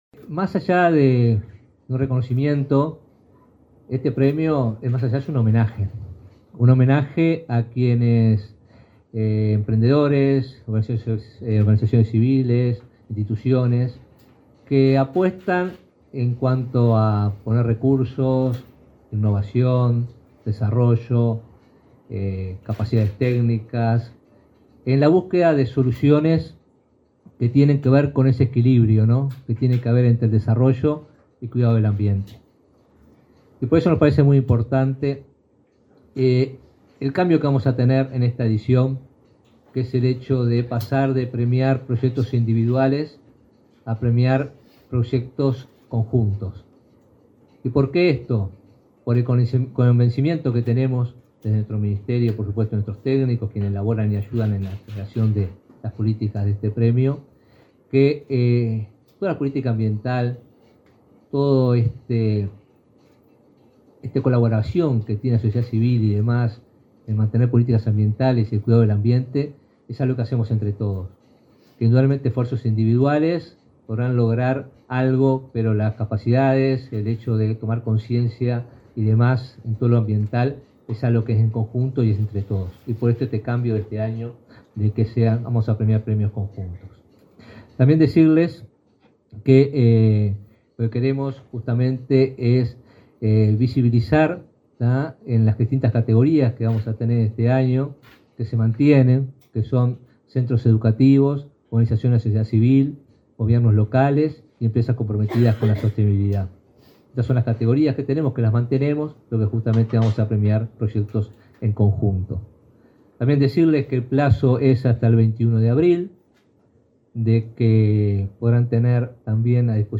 Palabras de autoridades en lanzamiento de 4.ª edición de Premio Nacional de Ambiente
El ministro de Ambiente, Robert Bouvier; el subsecretario de Industria, Walter Verri; el director nacional de Innovación, Ciencia y Tecnología del